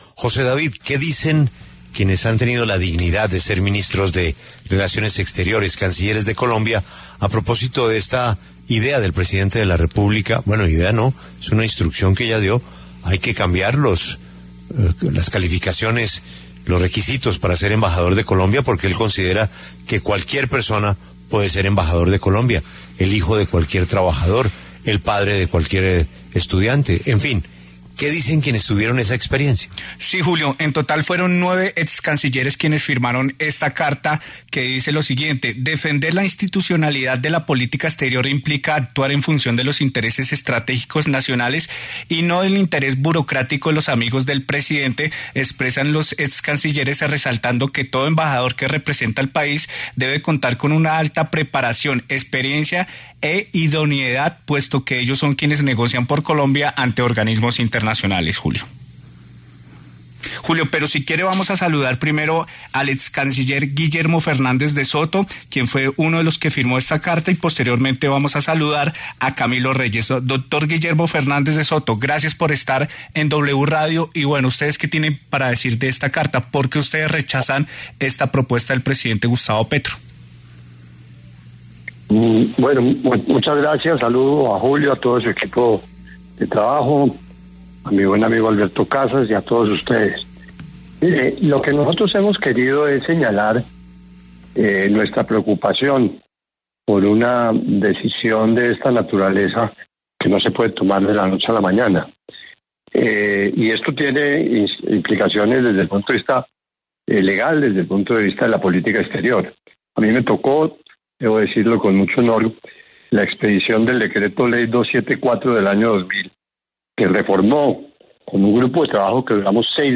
Los excancilleres Guillermo Fernández de Soto y Camilo Reyes hablaron en La W sobre la posibilidad de “democratizar” las embajadas y cambiar los requisitos para nombrar embajadores.